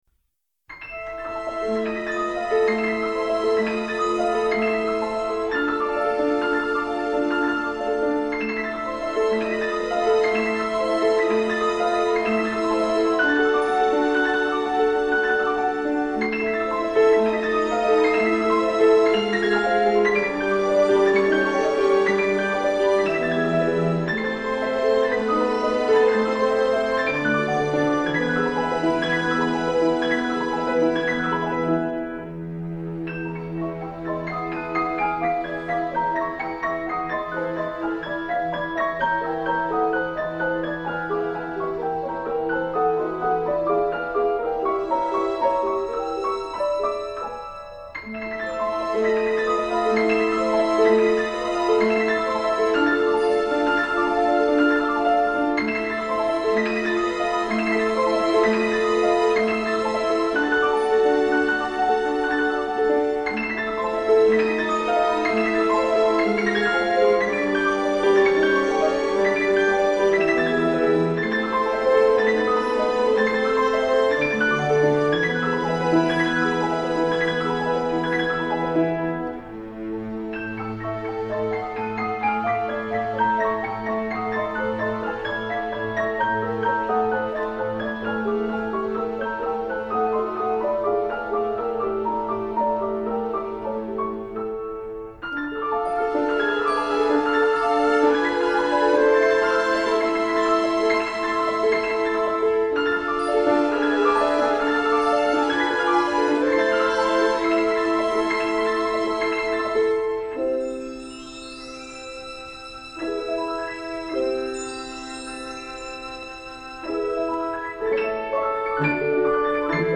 Musica_fons.mp3